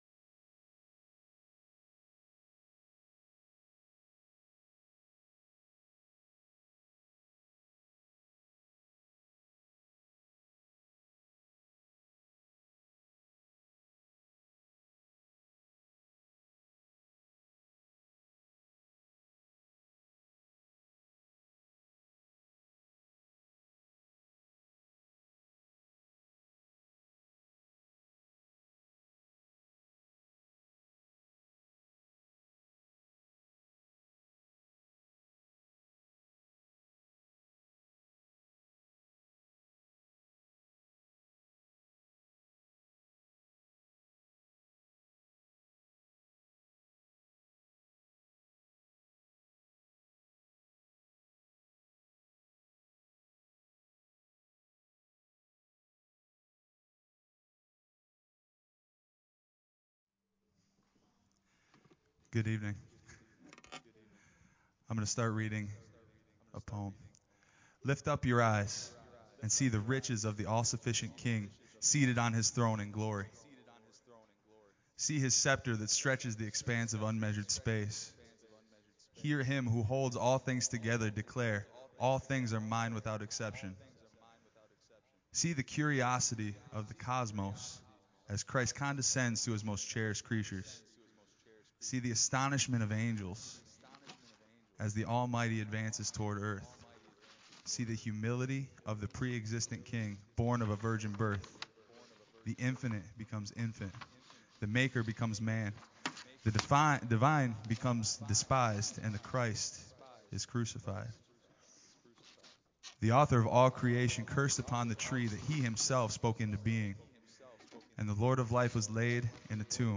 Praise Worship
Candle Lighting Ceremony